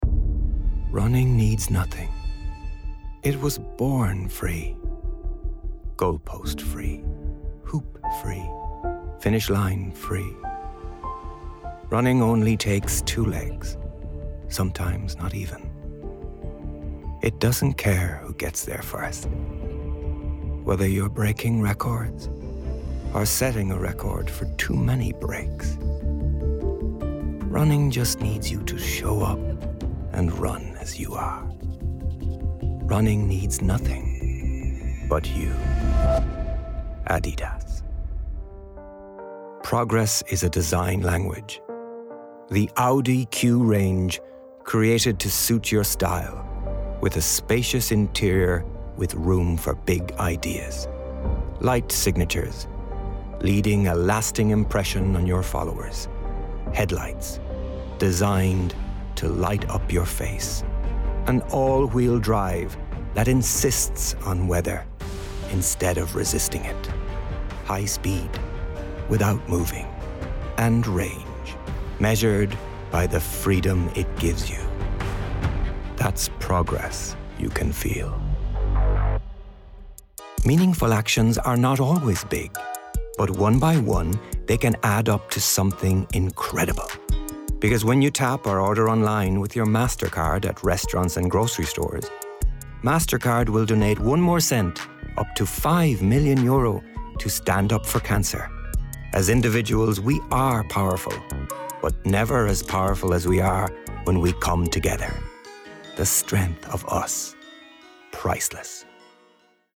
Male
40s/50s, 50+
Irish Dublin Neutral, Irish Neutral